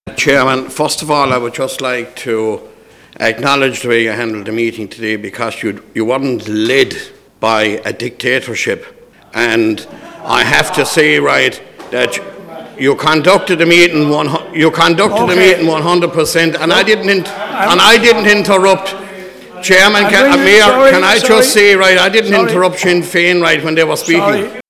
Newly-elected Deputy Mayor of Waterford, Cllr. Tom Cronin disputed Sinn Fein’s claims and said that Cllr. O’Leary chaired the meeting excellently as he wasn’t ‘led by a dictatorship’.
Tom-Cronin-Sinn-Fein.wav